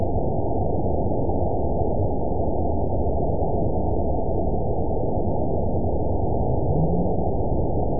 event 920384 date 03/20/24 time 22:46:34 GMT (1 year, 1 month ago) score 9.36 location TSS-AB03 detected by nrw target species NRW annotations +NRW Spectrogram: Frequency (kHz) vs. Time (s) audio not available .wav